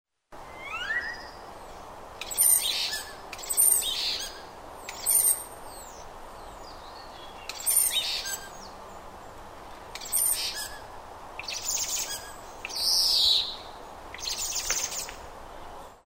Common Starling  Sturnus vulgaris
Sonogram of Starling vocalisations
Sunny Hill Park, London  23 March 2008, 0600h
Simple song or calls from rooftop.